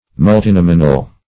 Meaning of multinominal. multinominal synonyms, pronunciation, spelling and more from Free Dictionary.
Search Result for " multinominal" : The Collaborative International Dictionary of English v.0.48: Multinominal \Mul`ti*nom"i*nal\, Multinominous \Mul`ti*nom"i*nous\, a. [L. multinominis; multus many + nomen nominis name.] Having many names or terms.